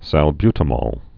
(săl-bytə-môl, -mōl, -mŏl)